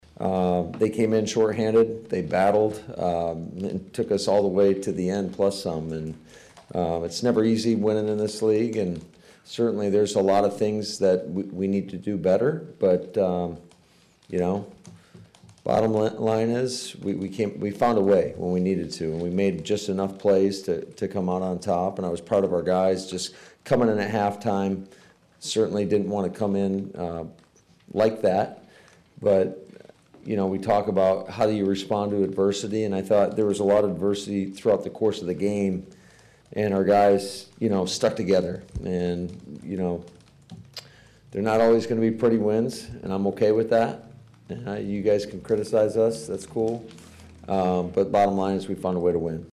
It was a very relieved Head Coach who met the media well after the overtime dust had settled.